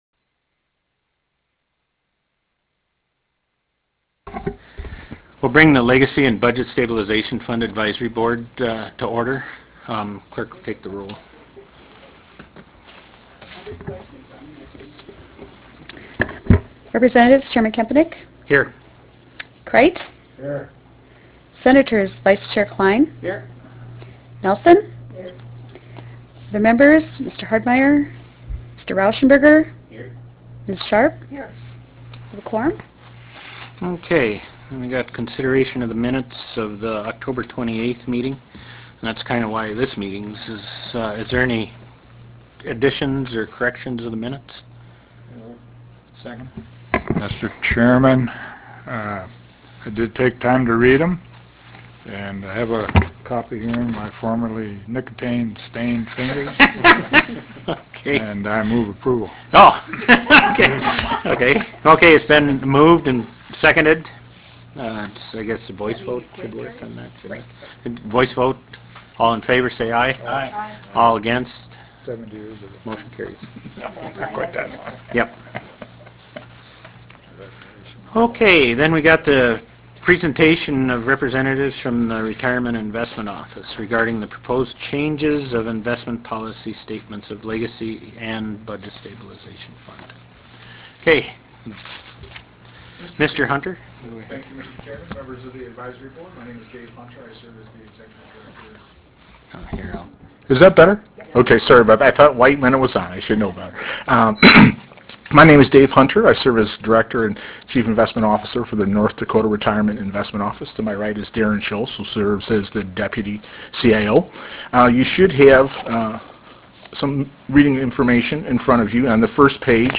Medora Room State Capitol Bismarck, ND United States